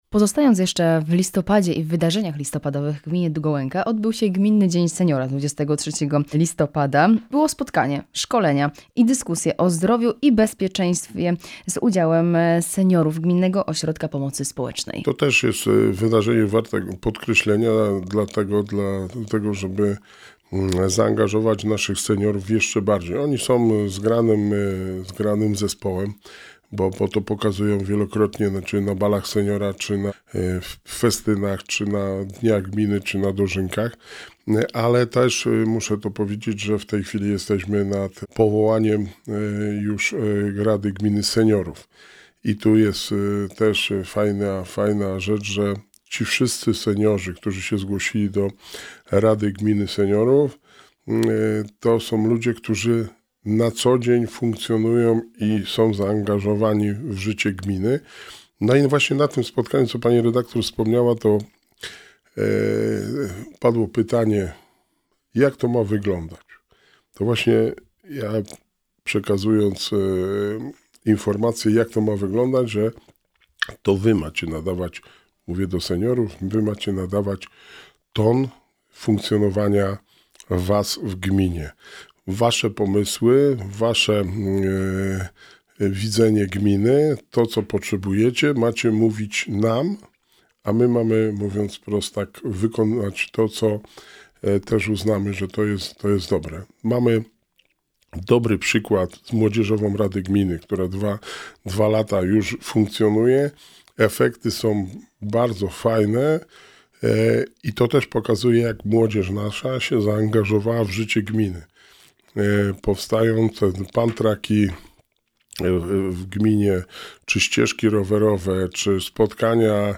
Rozmowa z Wójtem Gminy Długołęka
W studiu Radia Rodzina gościł Wojciech Błoński, wójt Gminy Długołęka. Rozmawiamy o otwarciu Gminnego Ośrodka Kultury, Spotkaniu Opłatkowym połączonym z Jarmarkiem Bożonarodzeniowym, a także o aktualnych i przyszłych inwestycjach.